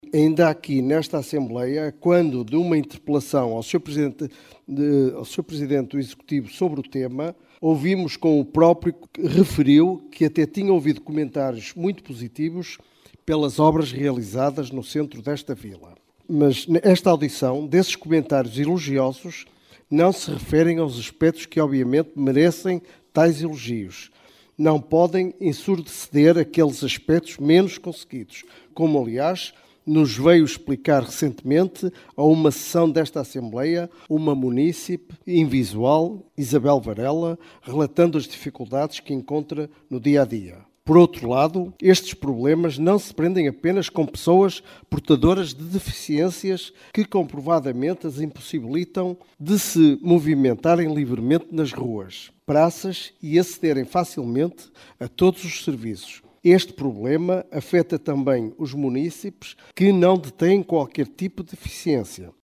Excertos da Assembleia Municipal extraordinária de 24 de Novembro.